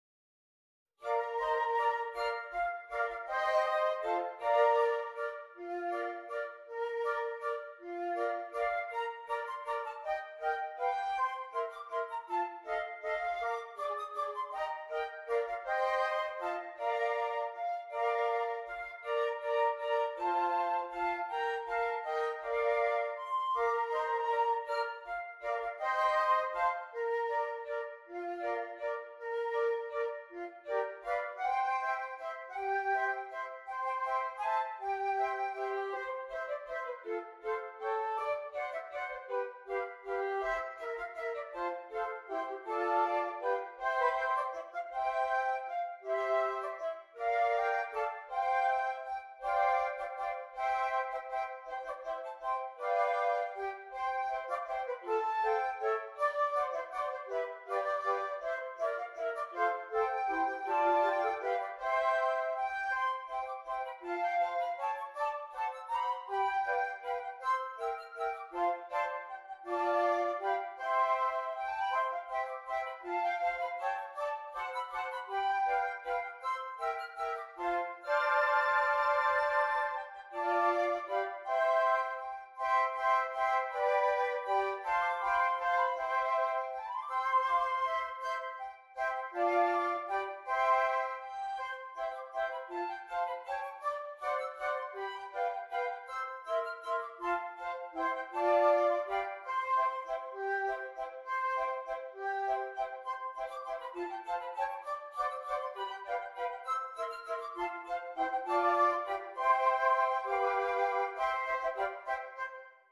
6 Flutes
Traditional Carol